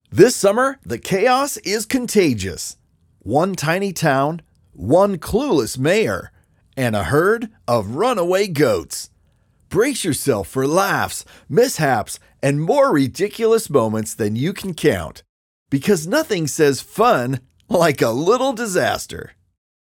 Trailer Demo
North American English, British (general)
- Professional recording studio and analog-modeling gear